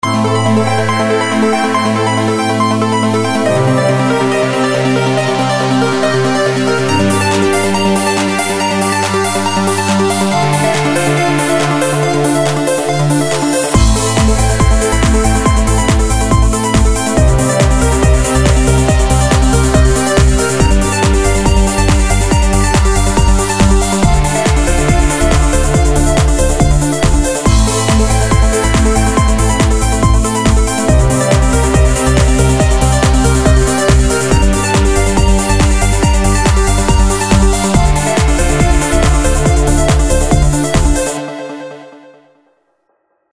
Реалтоны [4]
Короткие миксы